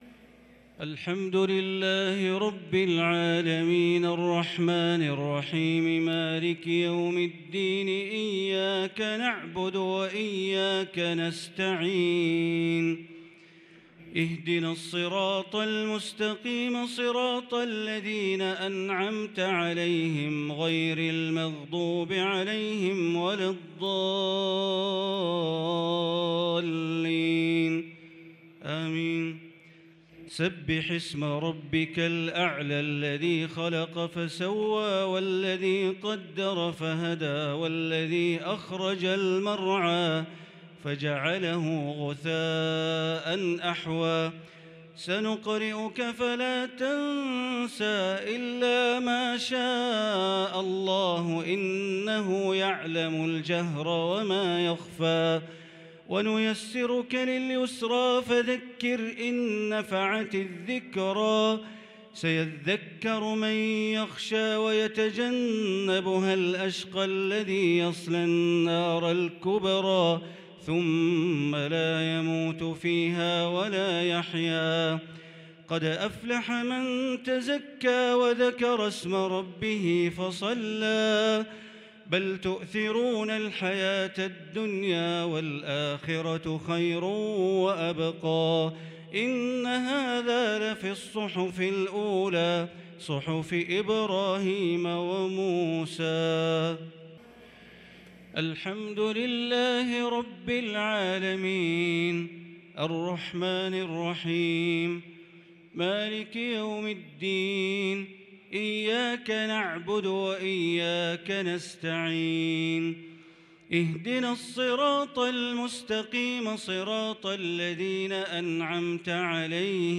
صلاة الشفع و الوتر ليلة 9 رمضان 1443هـ > تراويح 1443 > التراويح - تلاوات بندر بليلة